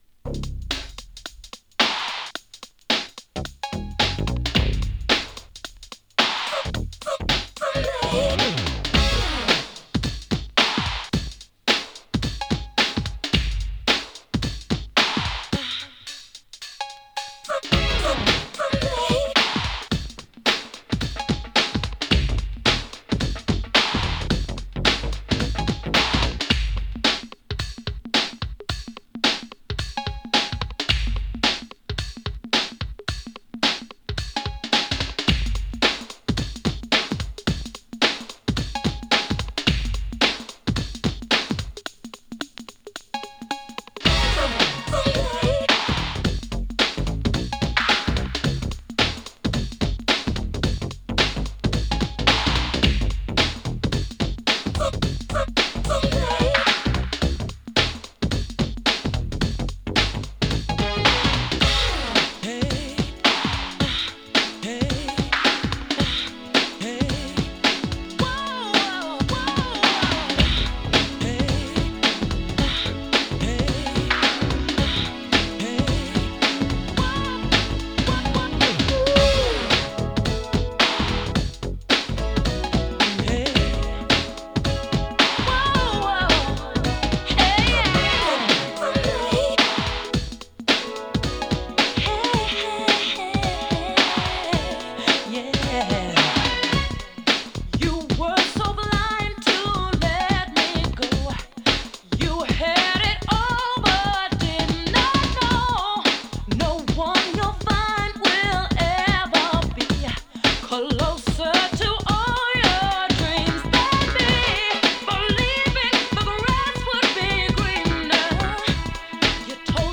90s RnB
切なくも力強さを感じさせる90s RnB！